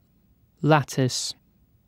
Ääntäminen
UK : IPA : /ˈlæt.ɪs/